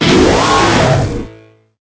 Cries
TOXTRICITY.ogg